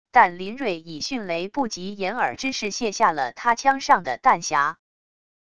但林锐以迅雷不及掩耳之势卸下了他枪上的弹匣wav音频生成系统WAV Audio Player